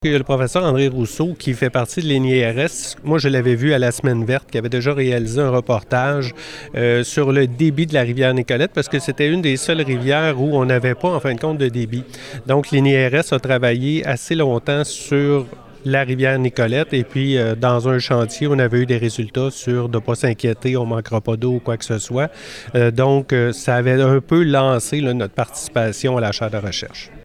Ce n’est pas un hasard si Nicolet a été choisie comme participante comme l’a précisé le conseiller municipal responsable du chantier sur l’eau, Stéphane Biron.